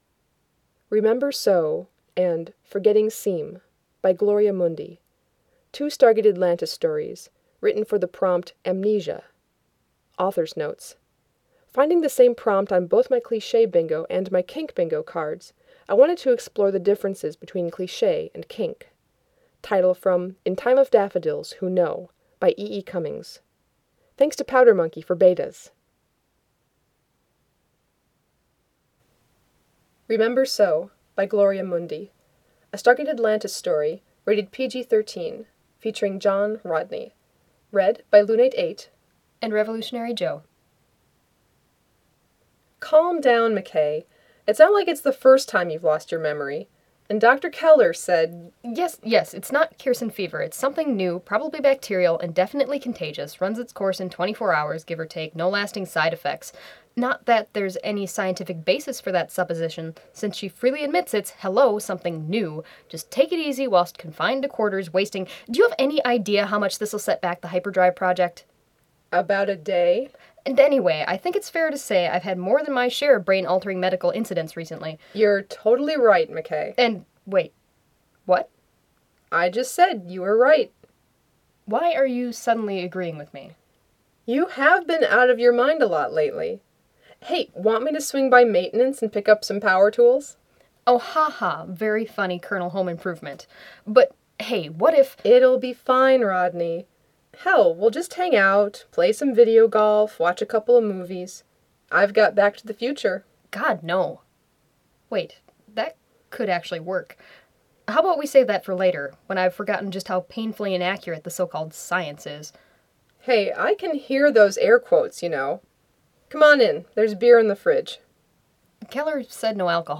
anthology|multiple reader, collaboration|two voices